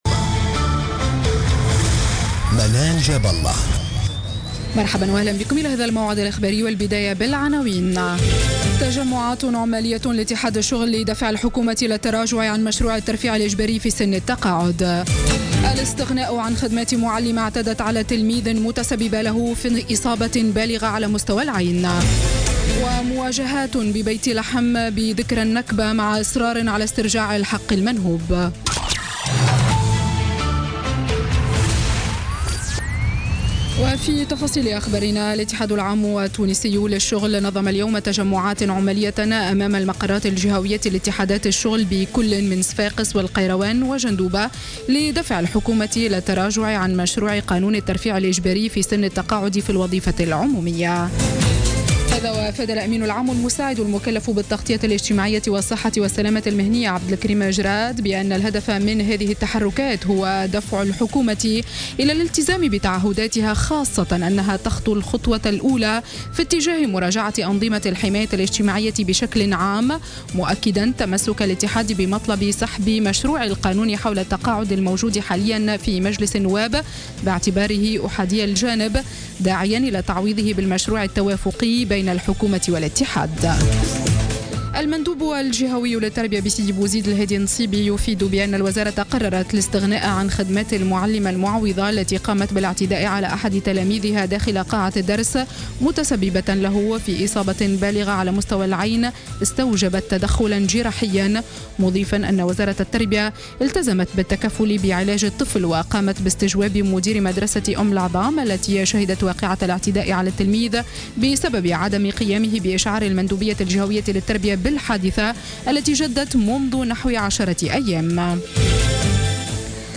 نشرة أخبار السابعة مساء ليوم الأحد 15 ماي 2016